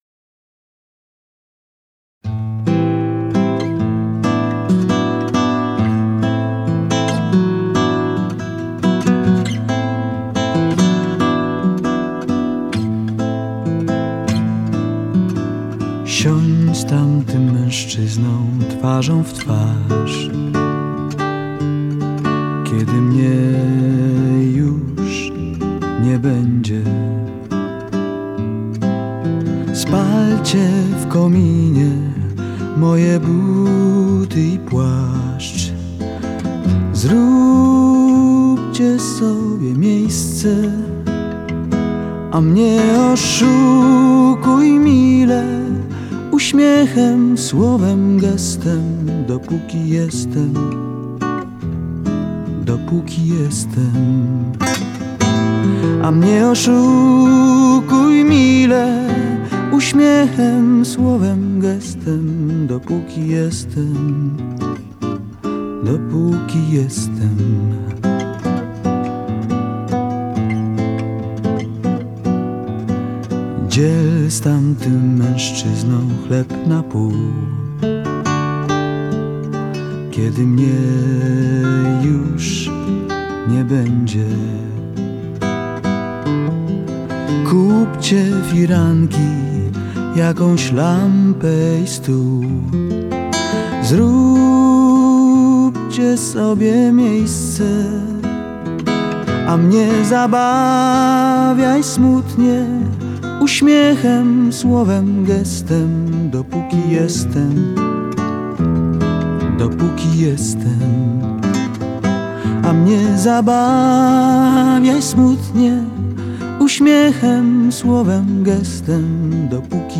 мелодичных композиций